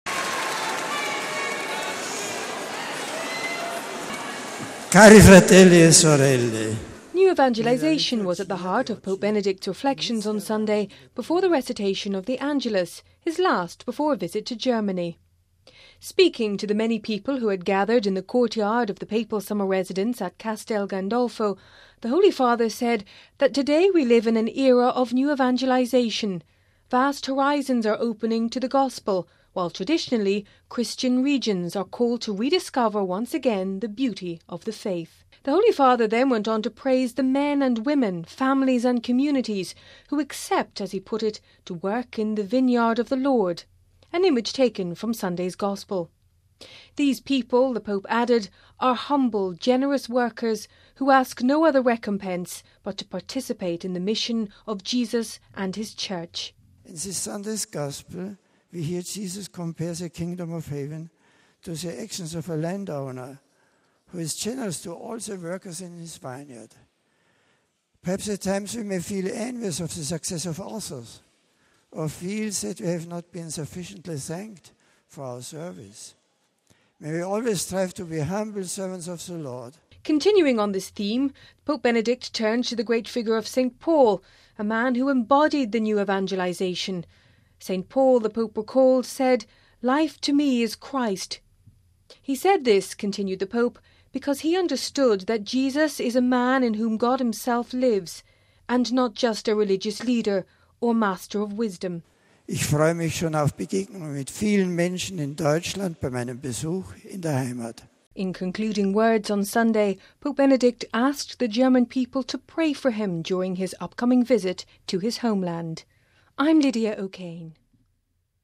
New evangelisation was at the heart of Pope Benedict’s reflections on Sunday before the recitation of the Angelus, his last before a visit to Germany.